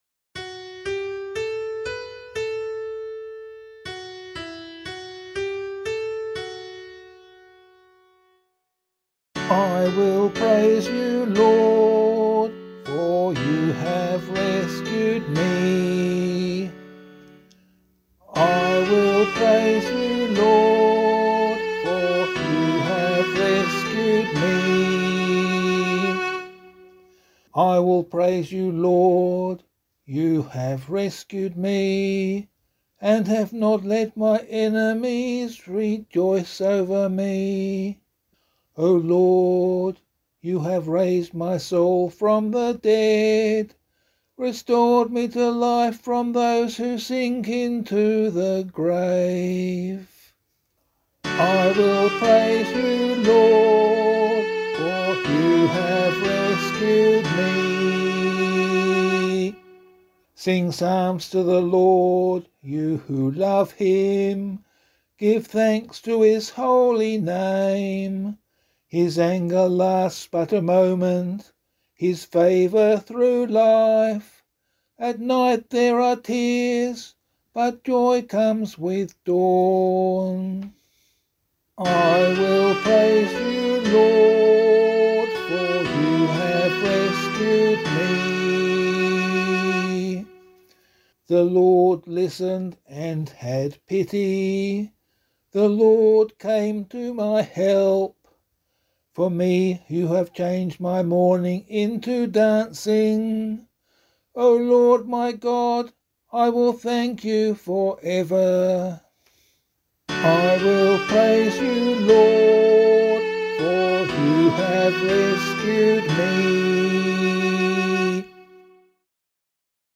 025 Easter 3 Psalm C [LiturgyShare 5 - Oz] - vocal.mp3